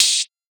Index of /musicradar/ultimate-hihat-samples/Hits/ElectroHat B
UHH_ElectroHatB_Hit-06.wav